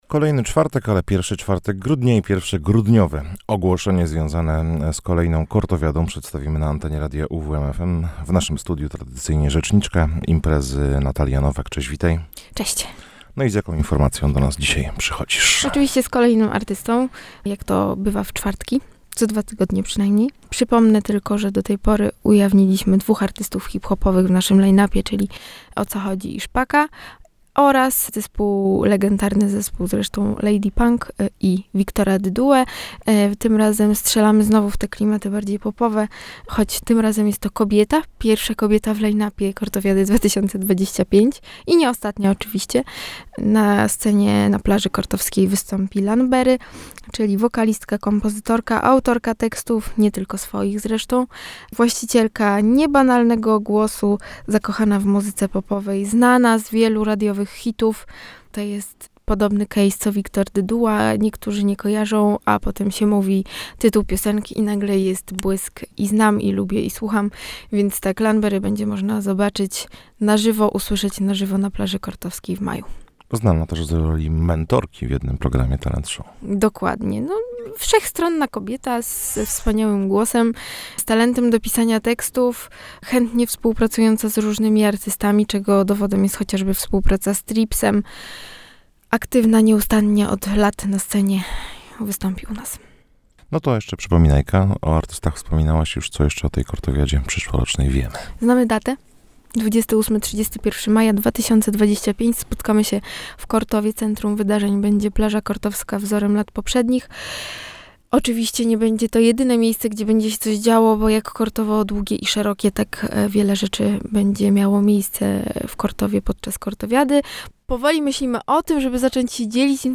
rozmawiał